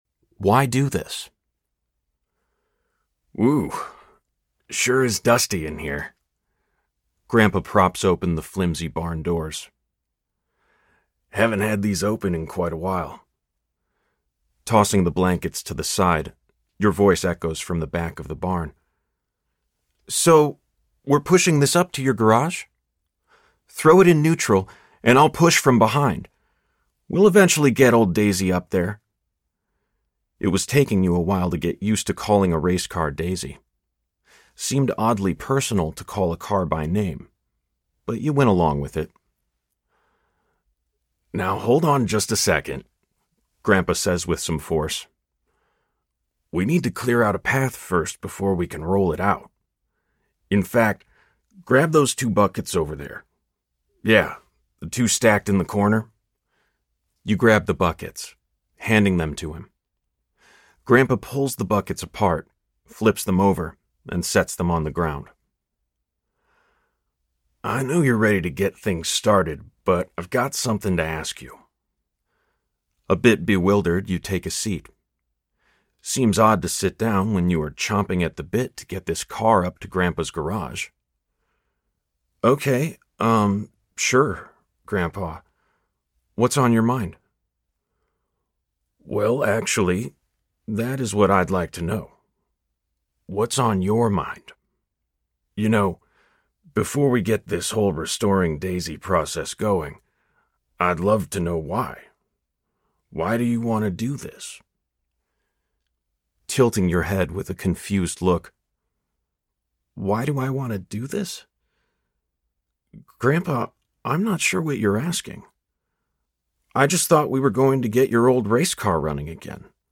Restored Man Audiobook
Narrator
3.5 Hrs. – Unabridged